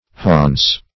(h[.a]ns)